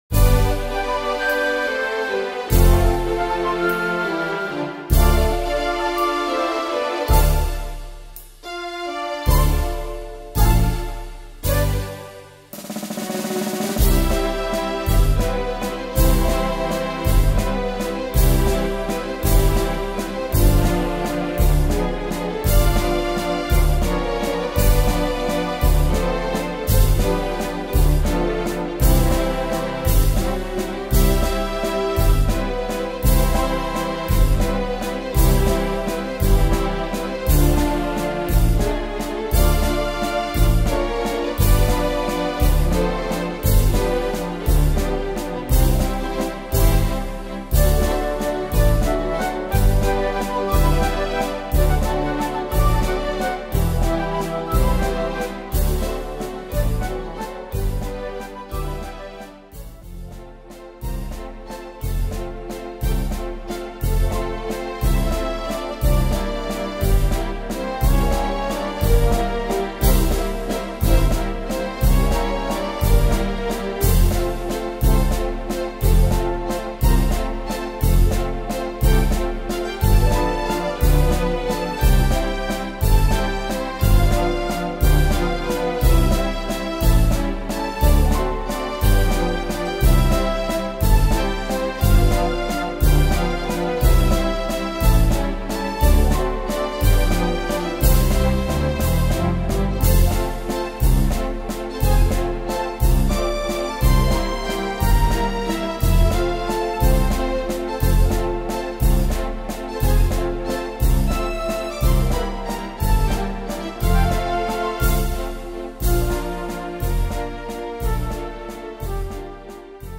Tempo: 150-180 / Tonart: C-Dur
Inst.